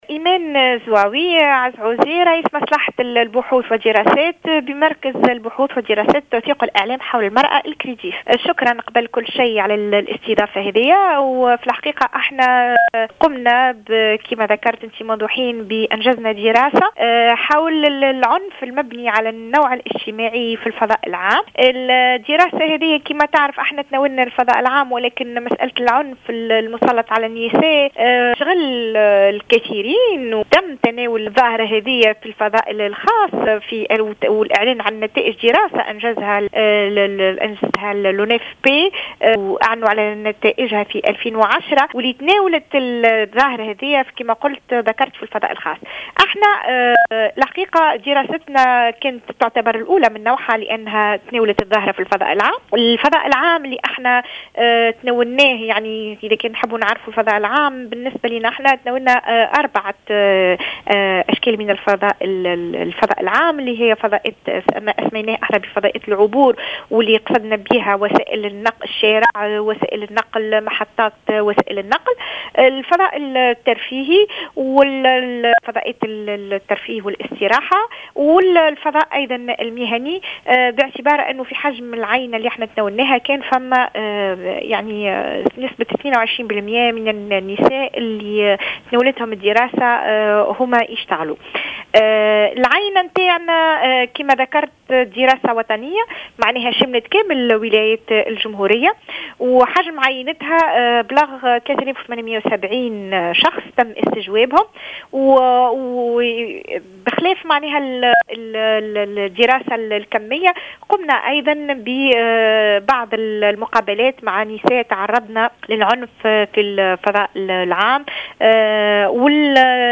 Dans une déclaration accordée à Jawhara FM